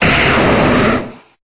Cri de Rhinoféros dans Pokémon Épée et Bouclier.